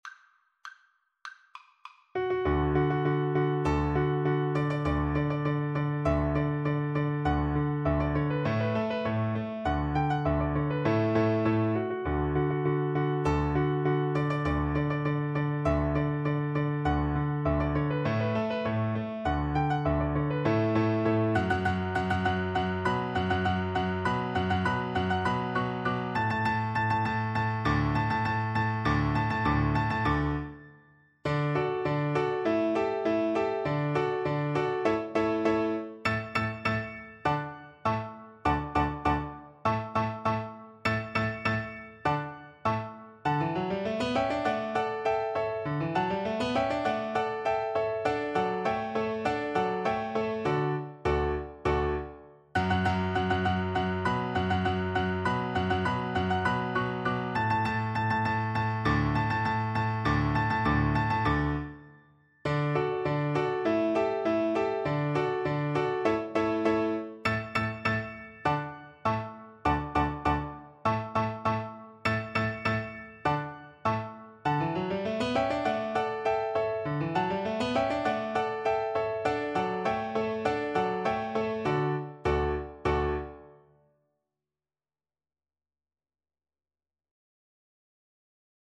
2/2 (View more 2/2 Music)
March = c.100